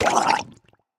Minecraft Version Minecraft Version latest Latest Release | Latest Snapshot latest / assets / minecraft / sounds / mob / drowned / water / hurt1.ogg Compare With Compare With Latest Release | Latest Snapshot
hurt1.ogg